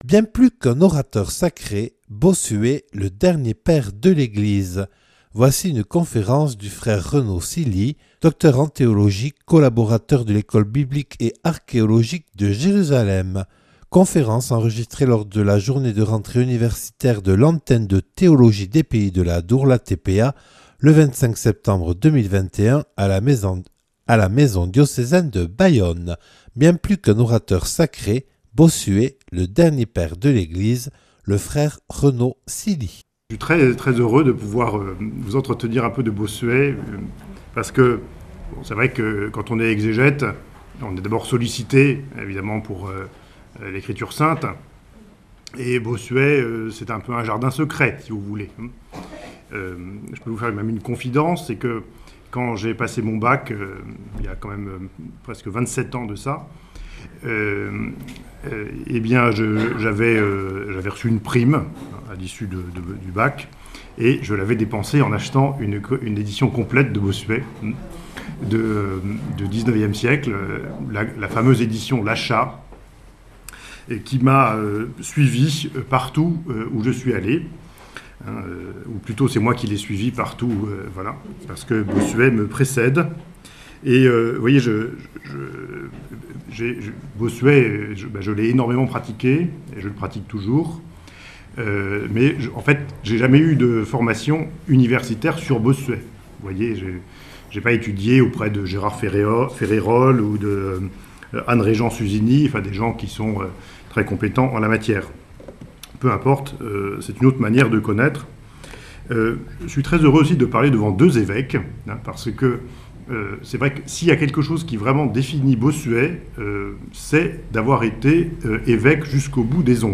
(Enregistré le 25/09/2021 à la maison diocésaine de Bayonne lors de la journée de rentrée universitaire de l’Antenne de Théologie des Pays de l’Adour ».